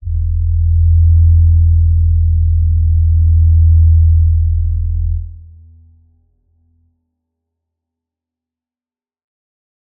G_Crystal-E2-pp.wav